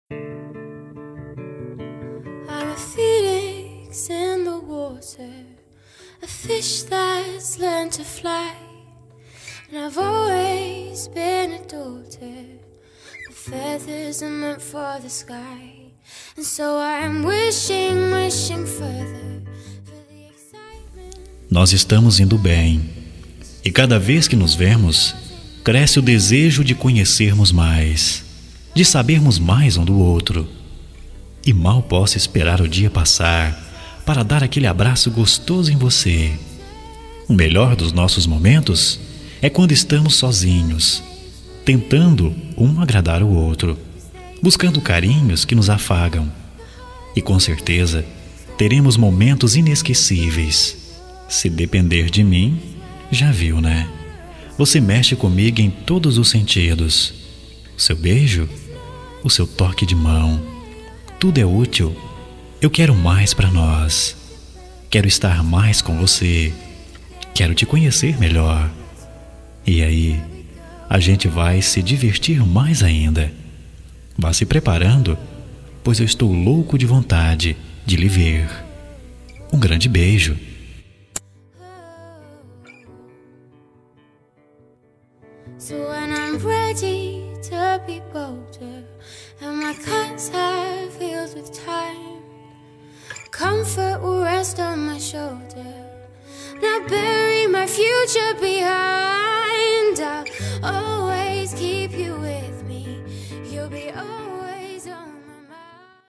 Voz Masculino